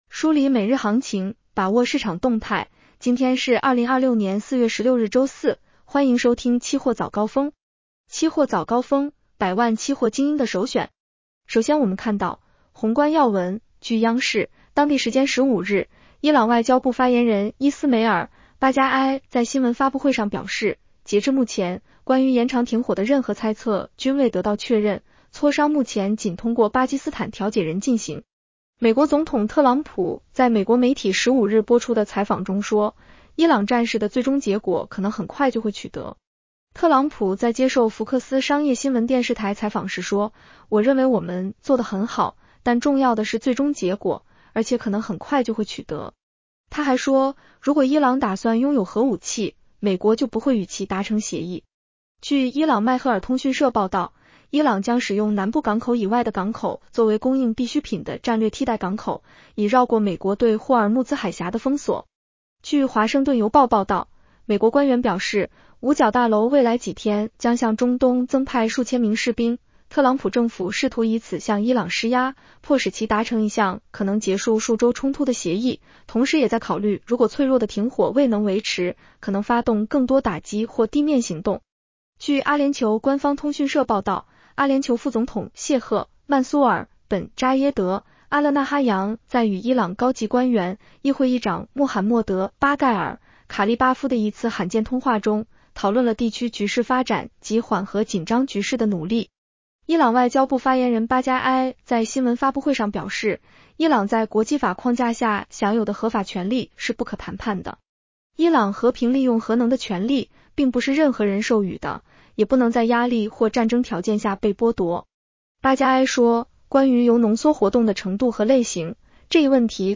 期货早高峰-音频版
期货早高峰-音频版 女声普通话版 下载mp3 热点导读 1.